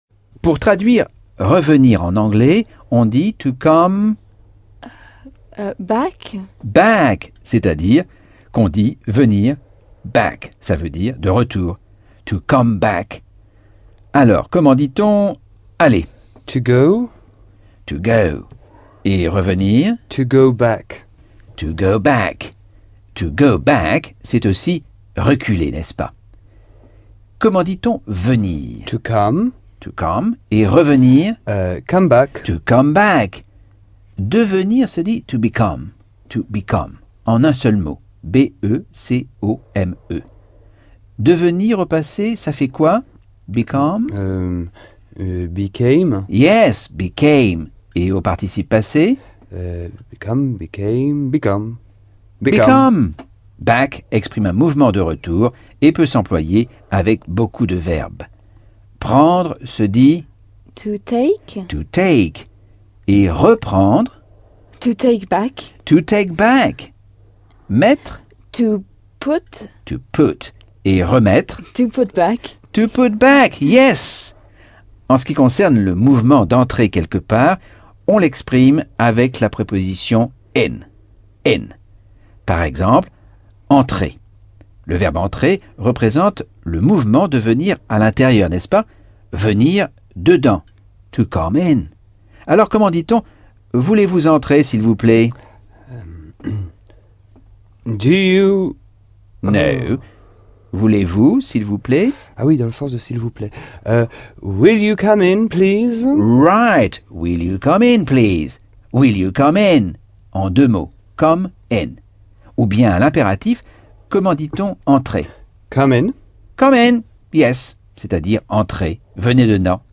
Leçon 10 - Cours audio Anglais par Michel Thomas - Chapitre 9